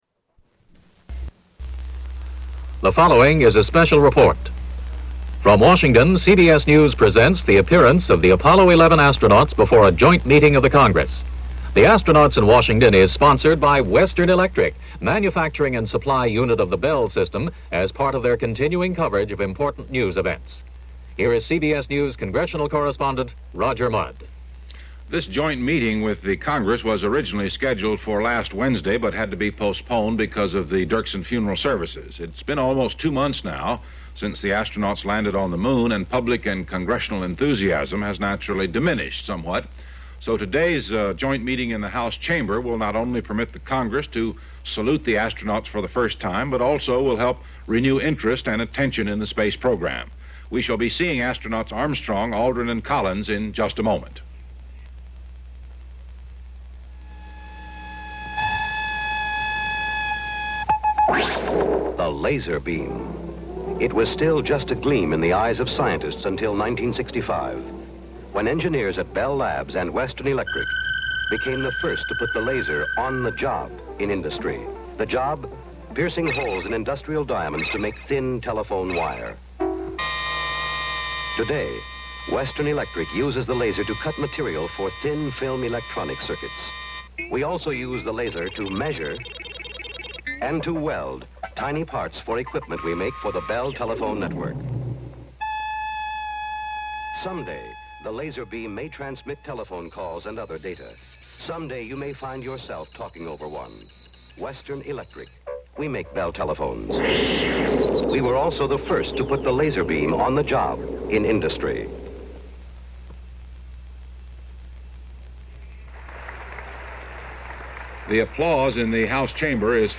On September 16, 1969 the astronauts of Apollo 11 addressed a joint meeting of Congress.